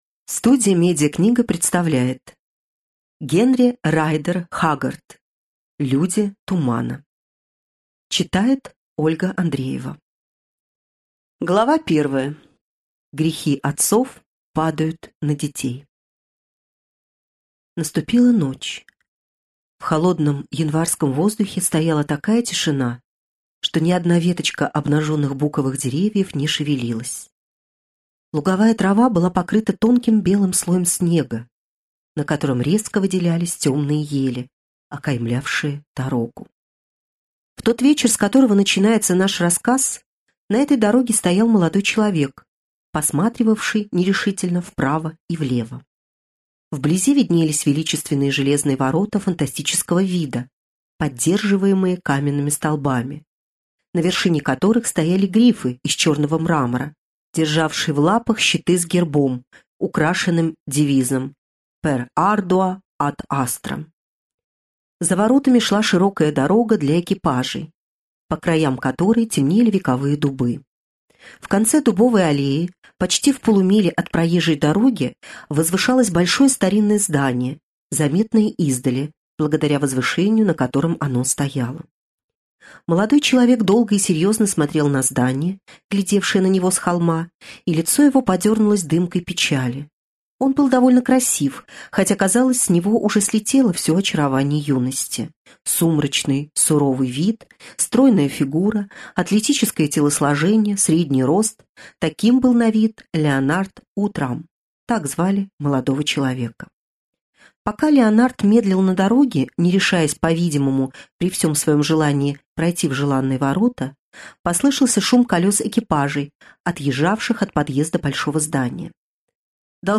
Aудиокнига